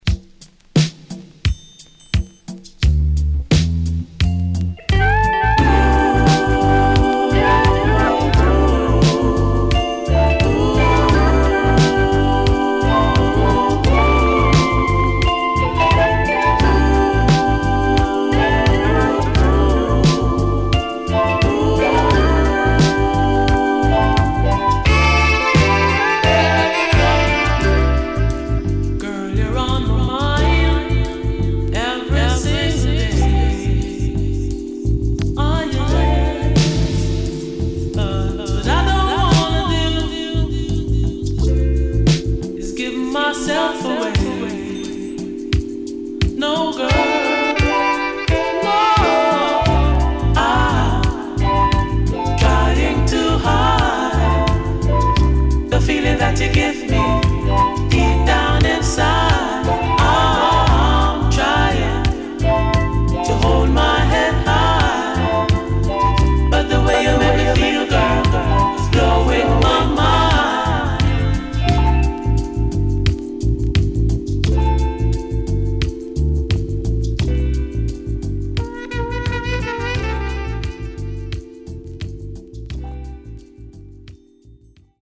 Nice Lovers Vocal.Good Condition